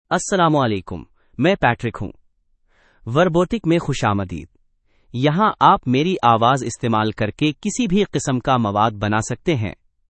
MaleUrdu (India)
Patrick is a male AI voice for Urdu (India).
Voice sample
Listen to Patrick's male Urdu voice.
Patrick delivers clear pronunciation with authentic India Urdu intonation, making your content sound professionally produced.